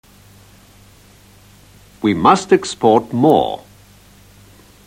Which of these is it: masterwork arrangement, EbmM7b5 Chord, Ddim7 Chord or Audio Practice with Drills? Audio Practice with Drills